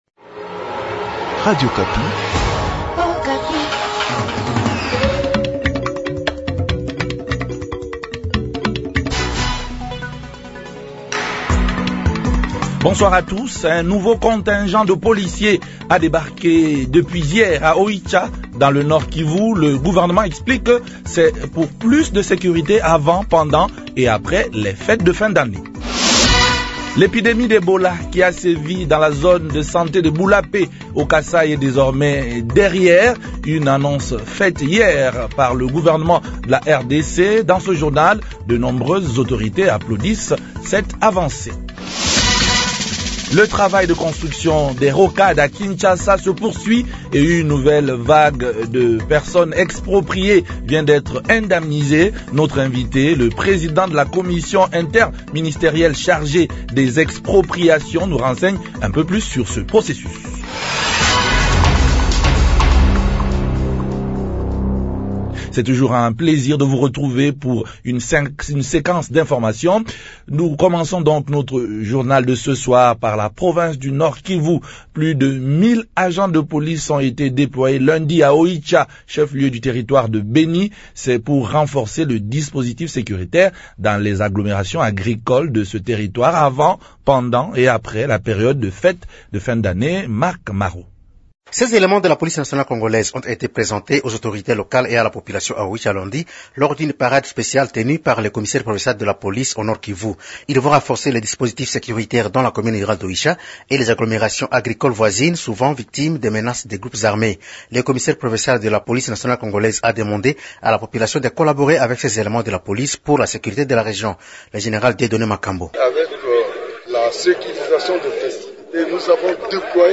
journal francais soir
Le travail de construction des rocades à Kinshasa se poursuit et une nouvelle vague de personnes expropriées vient d’être indemnisée. Notre invite, le président de la commission interministérielle chargée des expropriations nous renseigne un peu plus sur ce processus